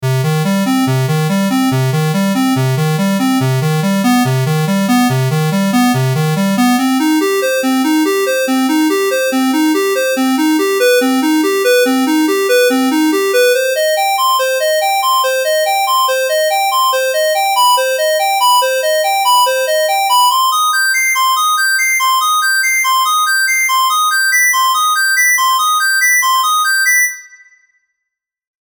They generate odd harmonics and the sound is a bit reminiscent of early video game sound.
Square wave
Square_-3dB.mp3